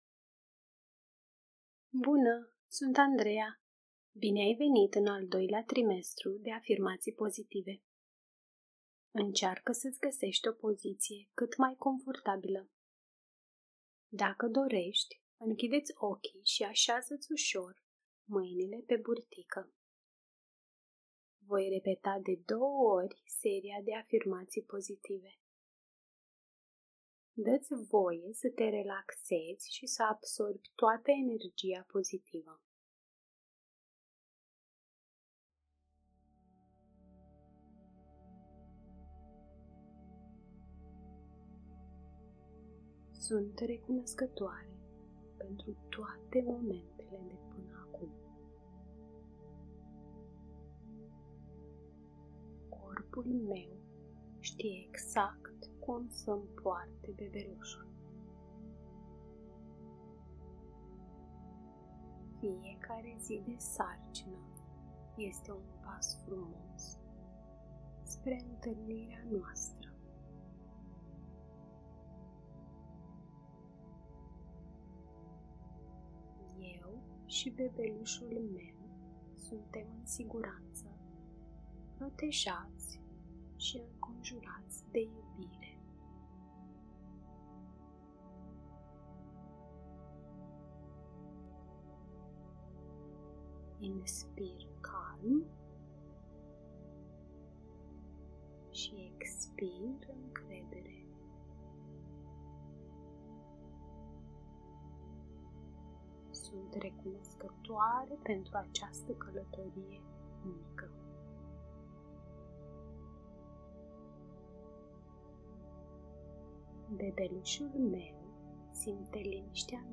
Un set de afirmații audio pentru a-ți întări încrederea în corpul tău și în procesul natural al nașterii.